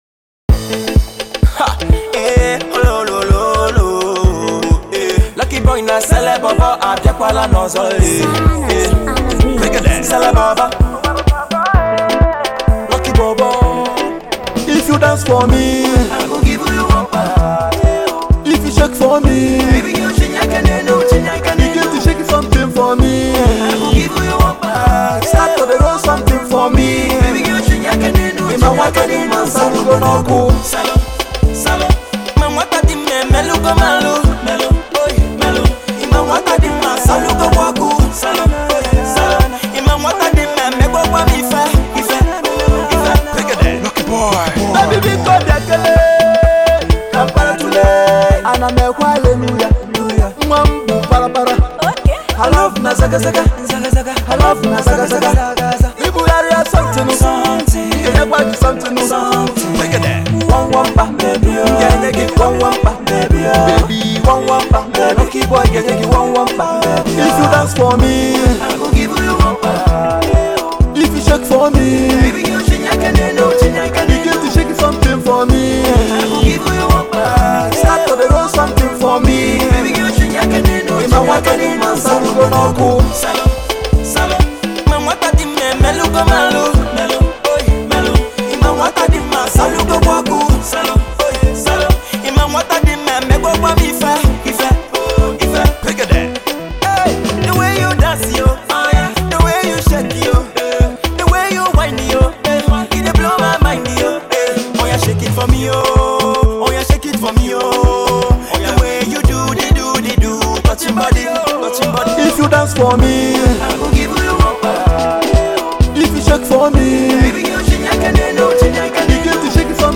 Igbo Music, Pop